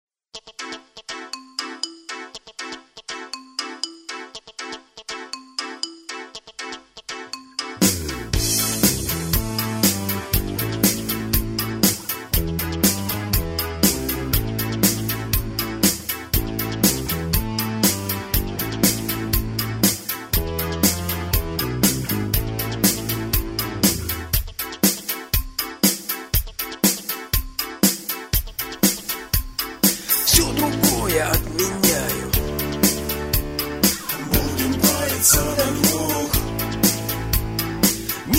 Поп (4932)